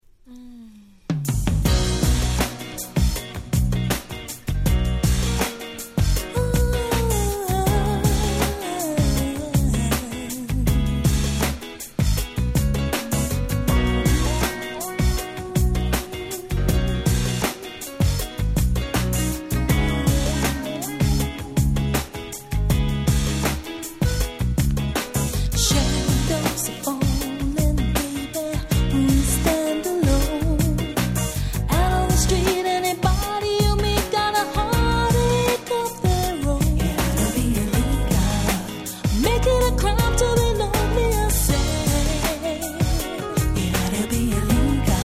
UK Soul Classics !!
これぞUK Soul！！と言った趣の爽やかかつ温かな1曲です。